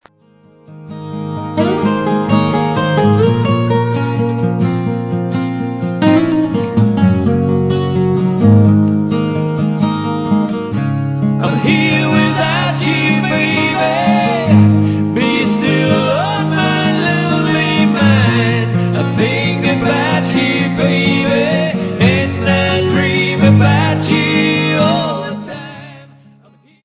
Guitar, Vocals
Vocals, 12-string guitar